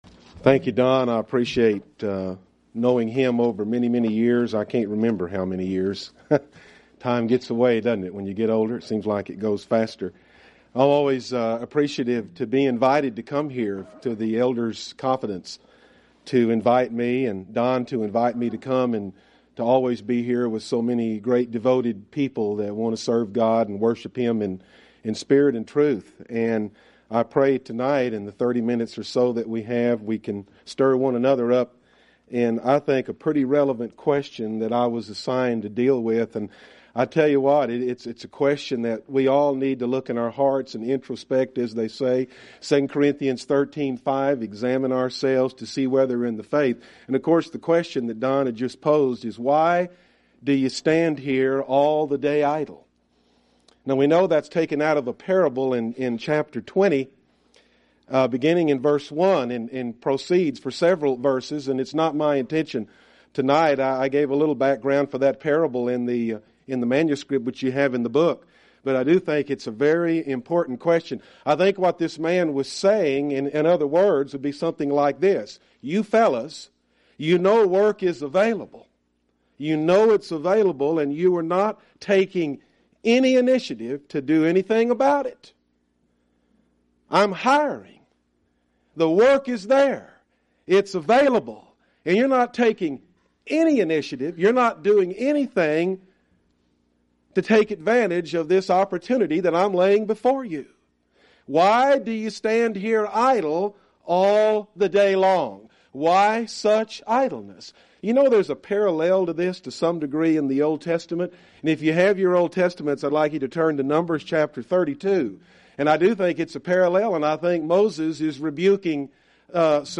Event: 2003 Annual Shenandoah Lectures
lecture